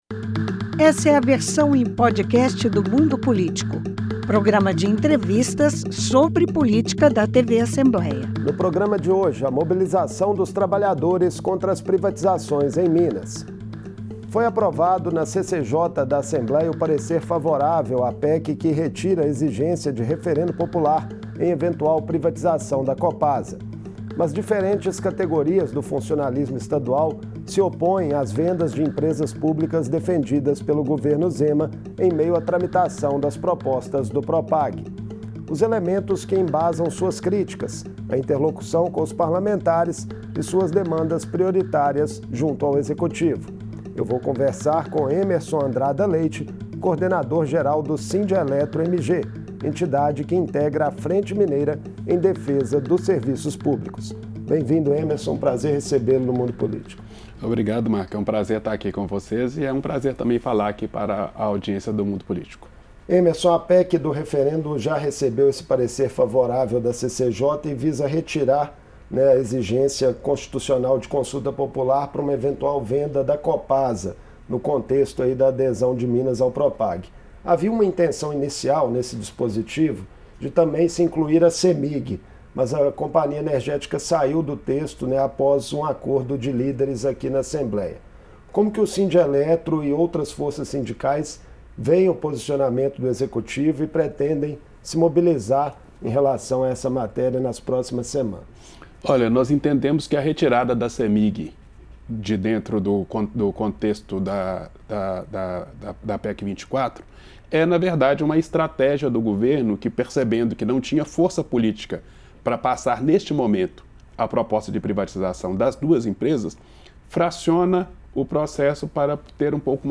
Foi aprovado na Comissão de Constituição e Justiça da Assembleia o parecer favorável à PEC que retira exigência de referendo popular para a venda da Copasa. Diferentes categorias dos servidores se opõem à privatização de estatais pelo governo de Minas, em meio à tramitação das propostas do Propag. Em entrevista